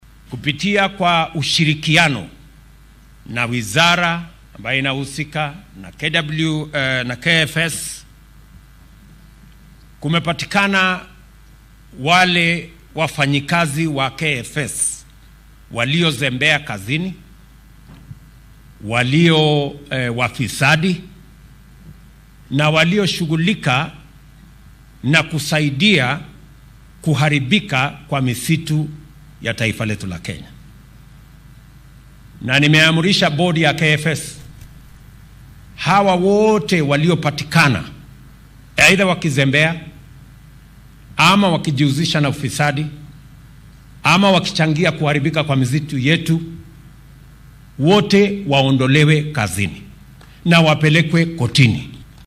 Arrintan ayuu ka sheegay magaalada Gilgil ee ismaamulka Nakuru oo ay ku qalinjabinayeen saraakiil ku biiraya adeegga keymaha ee dalka ee KFS oo uu tababar u soo idlaaday.